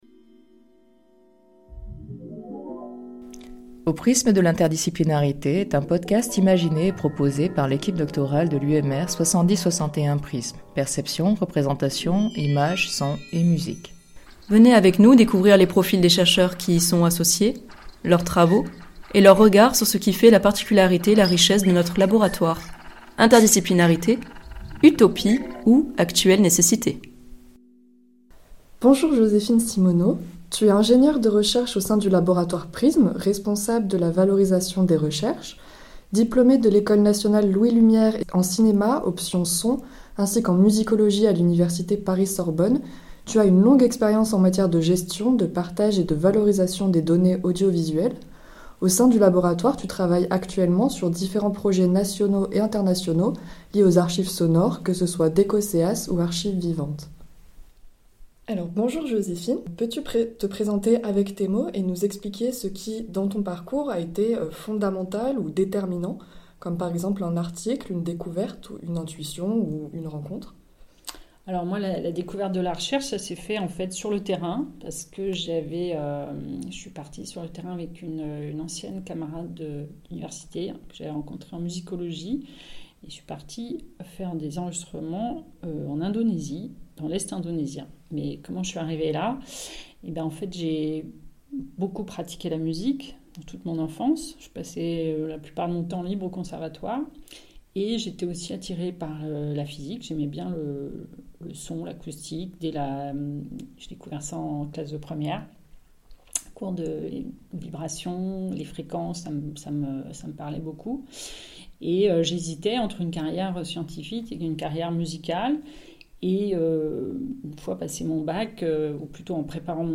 Cette série a pour objectif de présenter les profils et les projets de recherche des membres permanents et associés du laboratoire. Chaque entretien réalisé est l’occasion de présenter une vision de l’interdisciplinarité, notion chère à P.R.I.S.M.